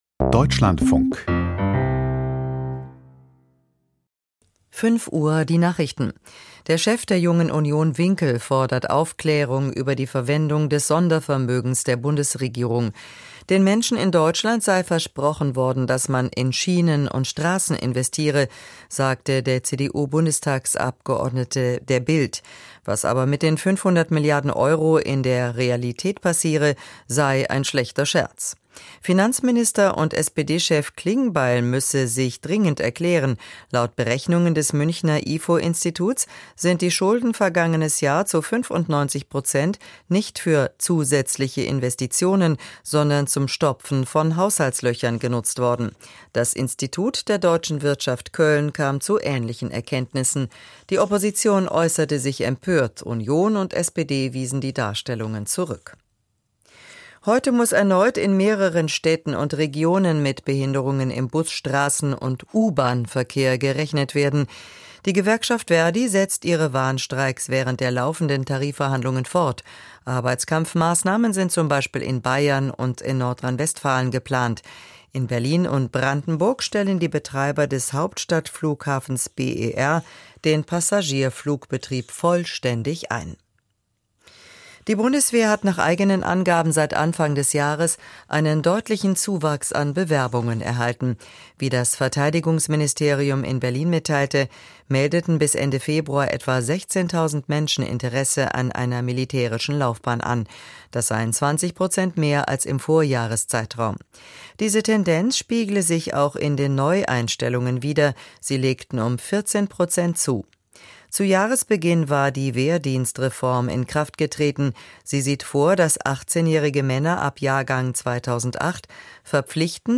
Die Nachrichten vom 18.03.2026, 05:00 Uhr
Die wichtigsten Nachrichten aus Deutschland und der Welt.
Aus der Deutschlandfunk-Nachrichtenredaktion.